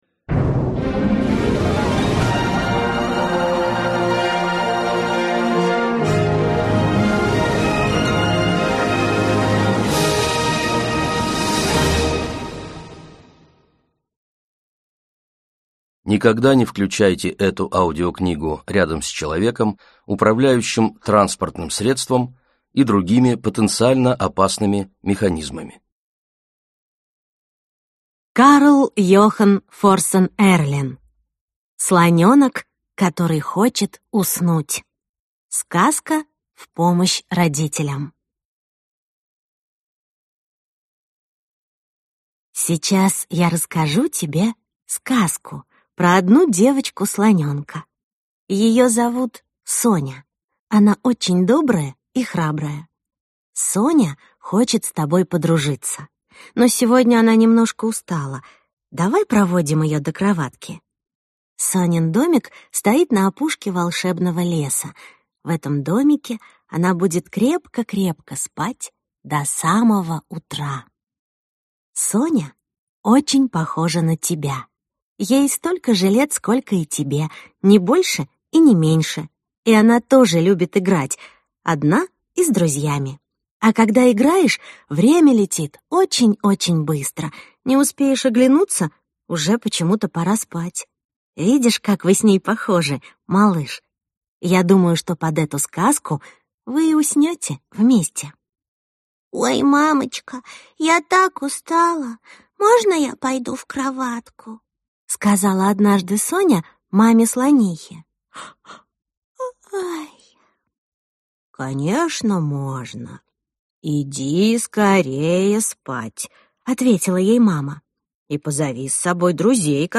Аудиокнига Слонёнок, который хочет уснуть.